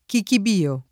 kikib&o; non kik&-] pers. m. — personaggio del Boccaccio: un suo buon cuoco, il quale era chiamato Chichibio, ed era viniziano [un S2o bUqj kU0ko, il kU#le Hra kLam#to kikib&o, ed $ra viniZZL#no] — kik&- pn. invalsa per dimenticanza tra ’700 e ’900